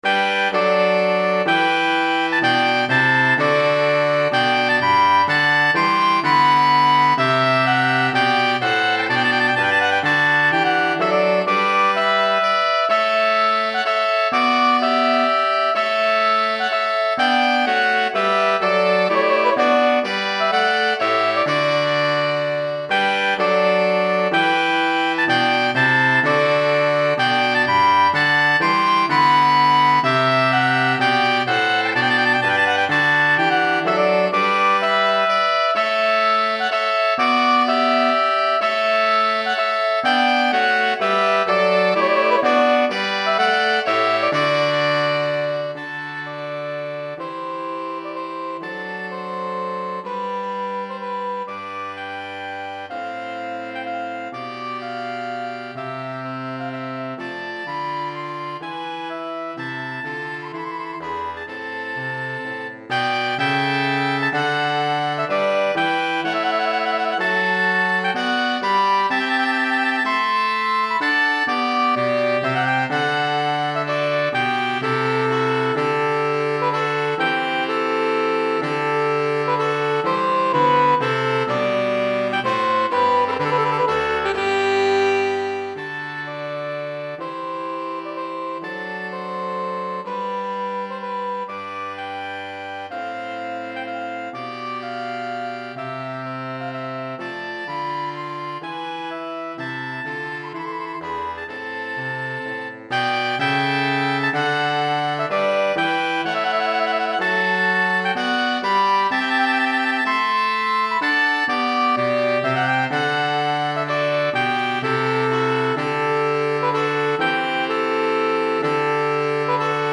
Saxophone Soprano, Saxophone Alto, Saxophone Ténor